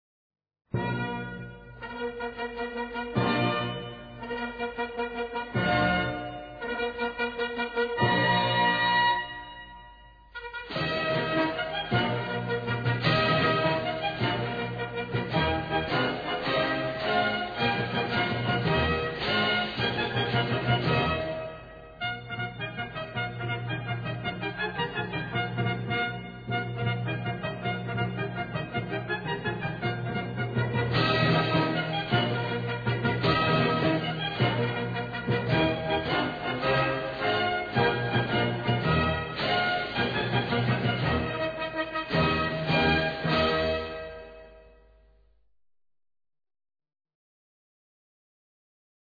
MARCHAS NAPOLEONICAS